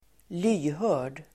Uttal: [²l'y:hör:_d]